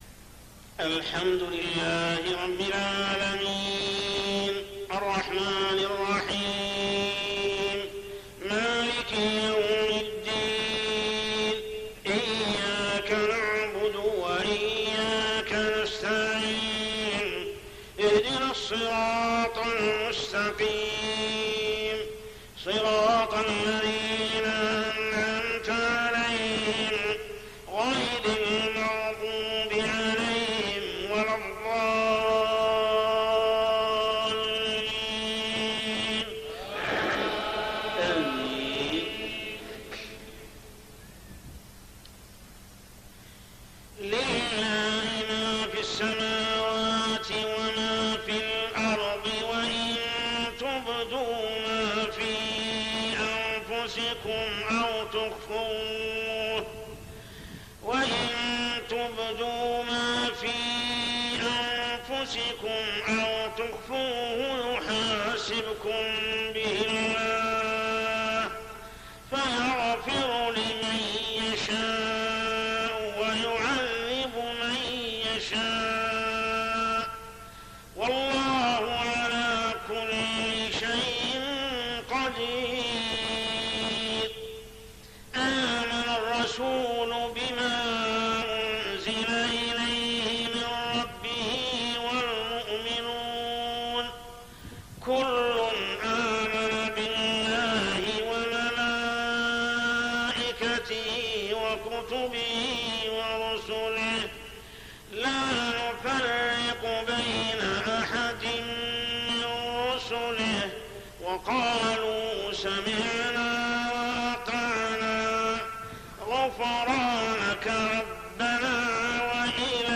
صلاة العشاء 3-9-1423هـ سورة البقرة 284-286 | Isha prayer Surah Al-Baqarah > 1423 🕋 > الفروض - تلاوات الحرمين